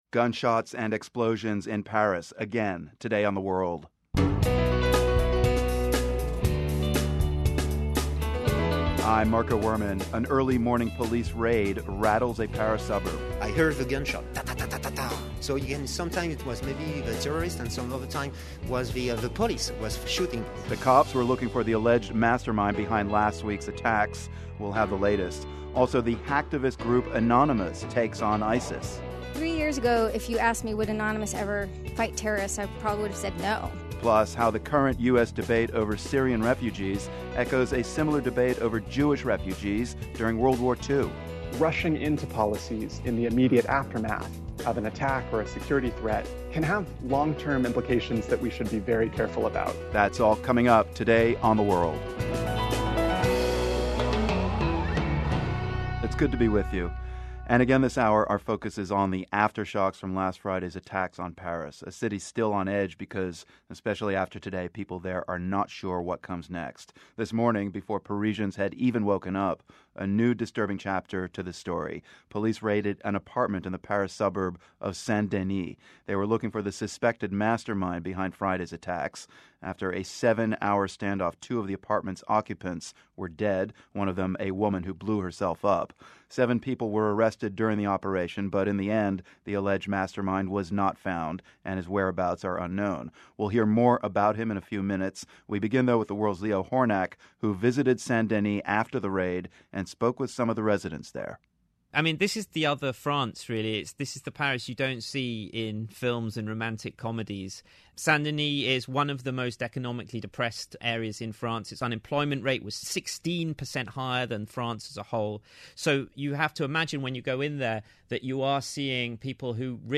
We also hear from some residents of a Belgian neighborhood where many of the suspected attackers lived. Plus, we hear more about the vetting process for refugees seeking to settle in the United States.